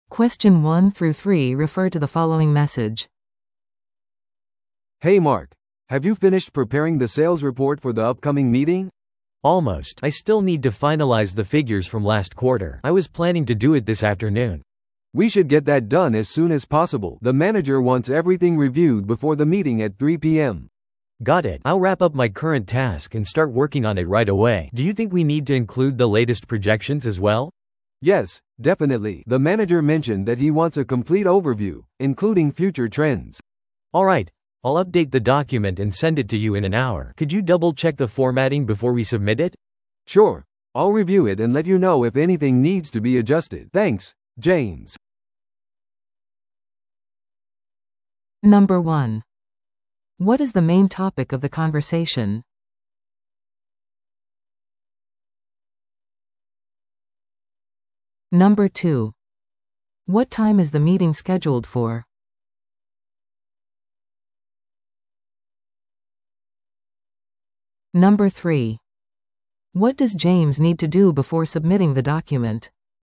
PART3は二人以上の英語会話が流れ、それを聞き取り問題用紙に書かれている設問に回答する形式のリスニング問題です。